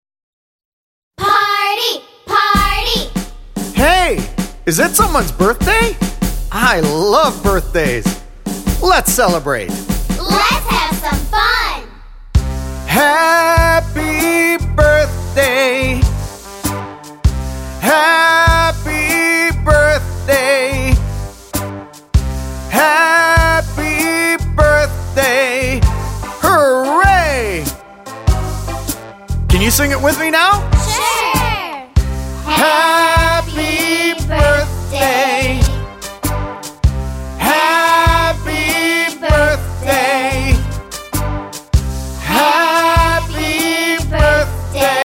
-23 simple, catchy songs
-Kids and adults singing together and taking verbal turns